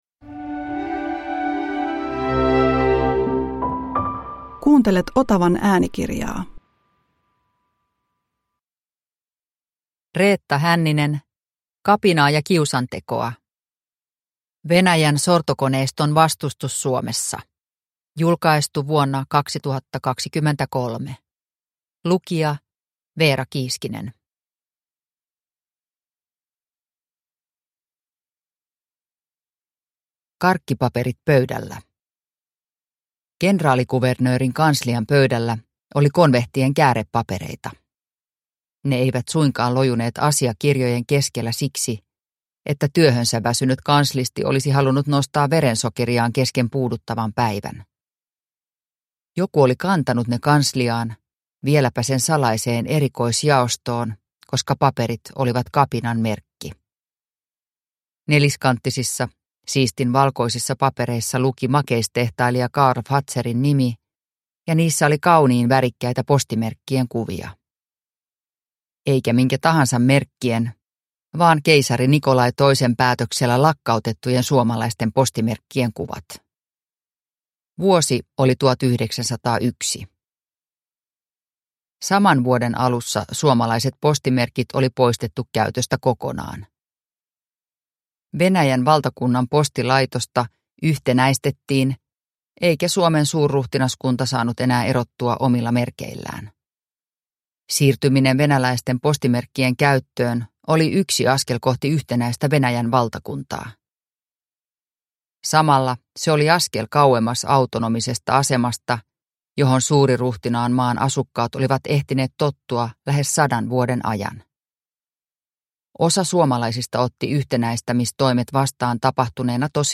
Kapinaa ja kiusantekoa – Ljudbok – Laddas ner